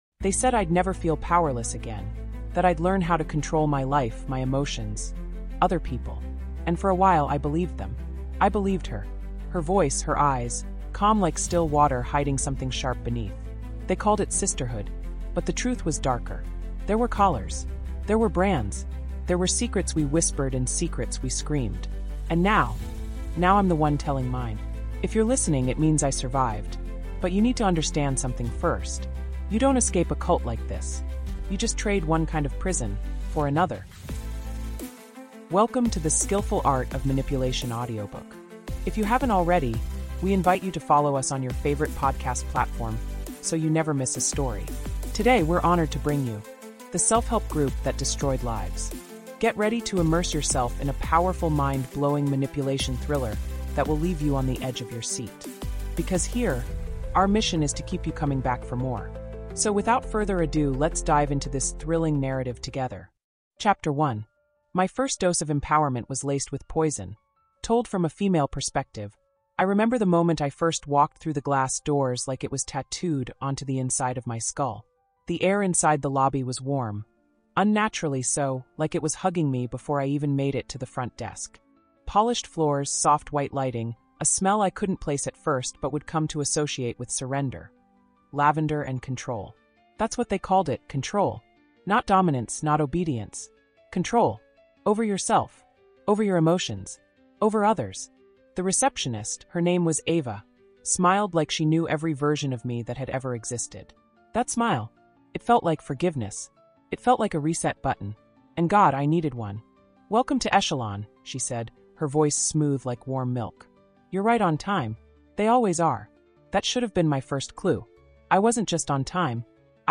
Told through raw, brutally honest first-person narration, this story peels back the layers of an elite secret society that branded its members, starved them into submission, and weaponized their deepest vulnerabilities. What began as a search for purpose turns into a slow, chilling descent into gaslighting, manipulation, and ultimate betrayal.